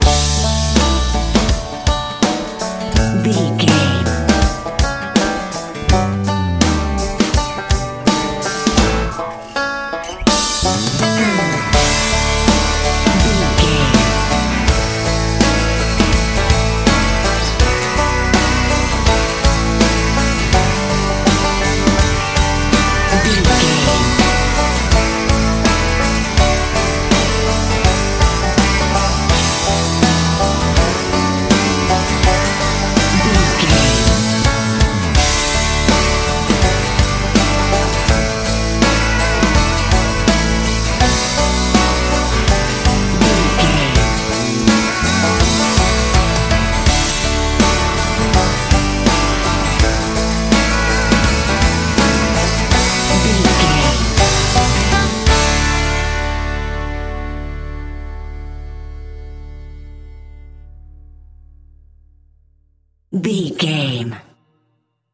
Ionian/Major
drums
electric piano
electric guitar
bass guitar
banjo
Pop Country
country rock
bluegrass
happy
uplifting
driving
high energy